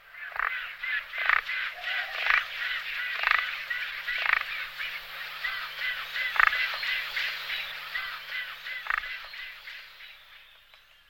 Звуки пеликана
В коллекции два натуральных аудиофрагмента с голосами этих удивительных птиц.